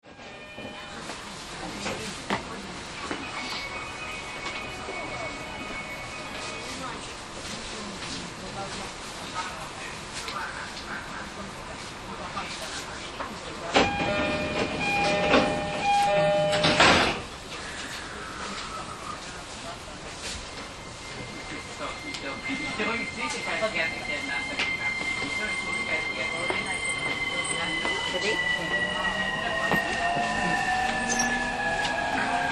しかしながら不思議なモーター音と挟まれたくないドアは健在です。
走行音
TK01 E231系 湯河原→真鶴 3:39 9/10 --